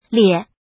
怎么读
liè
lie3.mp3